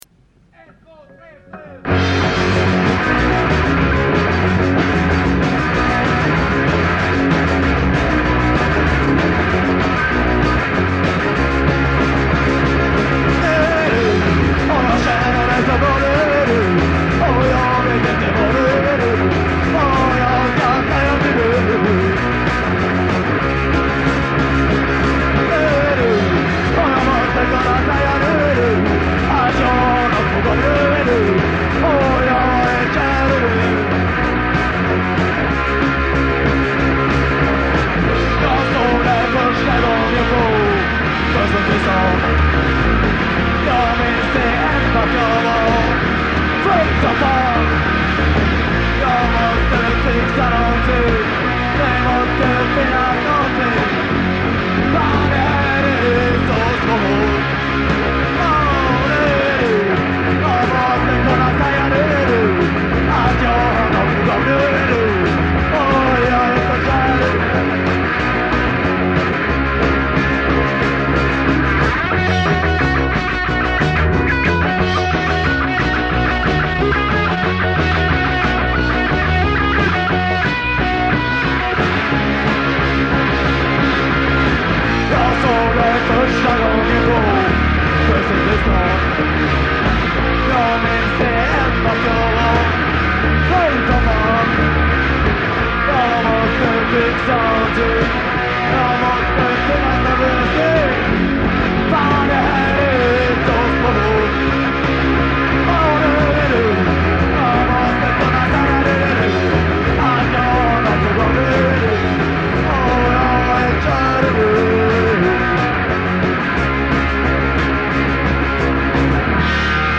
Live Errols 1980